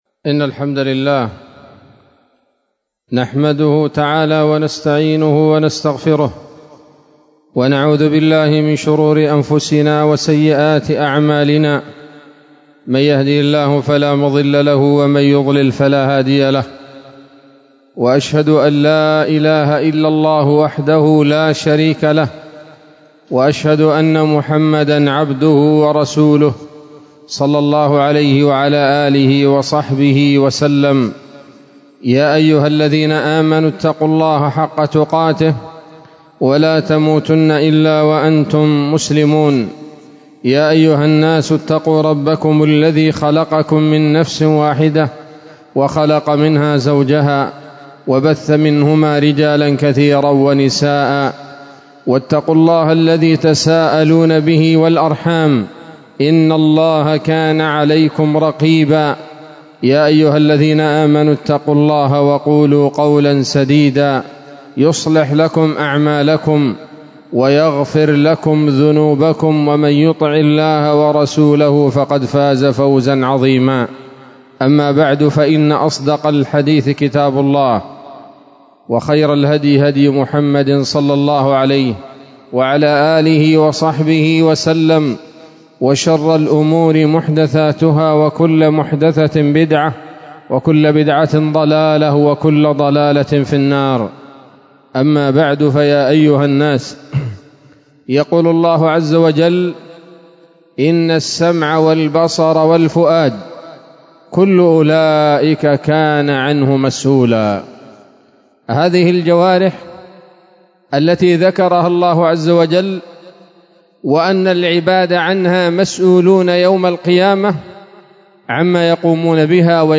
خطبة جمعة بعنوان: ((علامات القلب السليم )) 17 جمادى الأولى 1445 هـ، دار الحديث السلفية بصلاح الدين